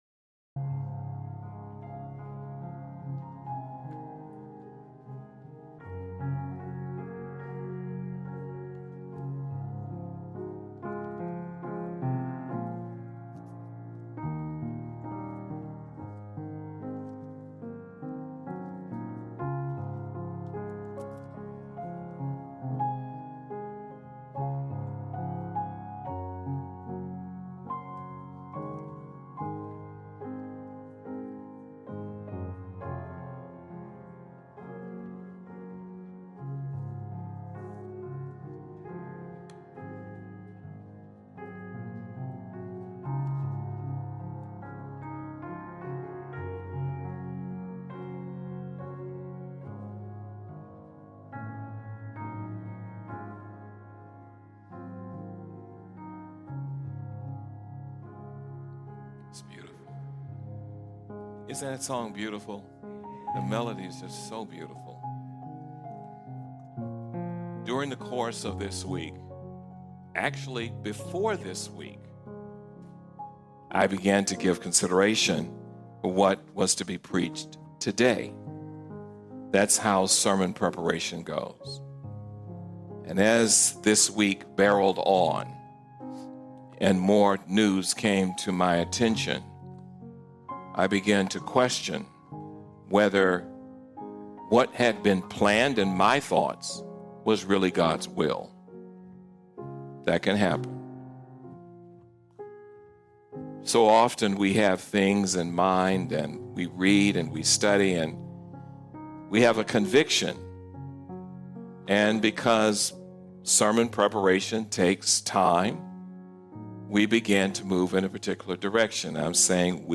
💡 Jesus as the Light Amidst Darkness: The service repeatedly underscores that Jesus Christ is the only true source of light and salvation.